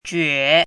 怎么读
juě jué
jue3.mp3